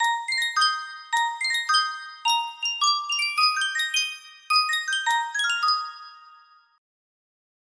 Reuge Miniature Music Box - Silent Night music box melody
Full range 60